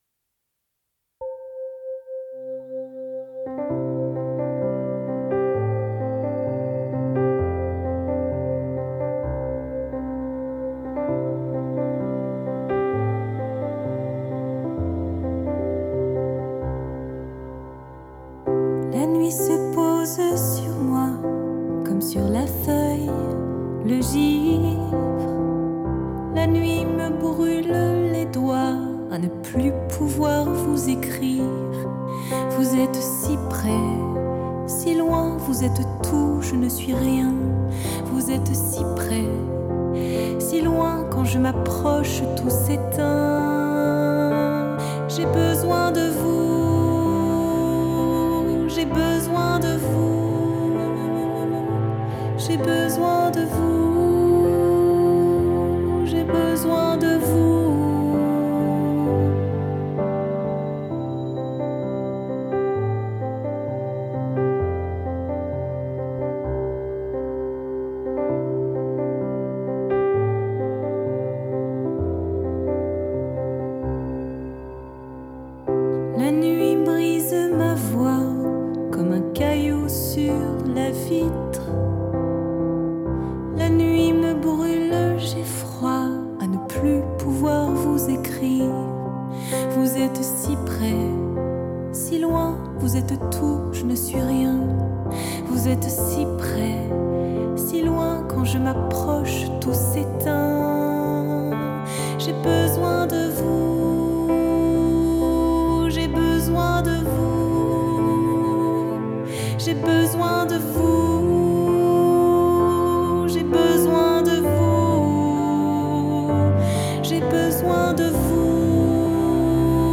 CHANT: ENREGISTREMENT EN STUDIO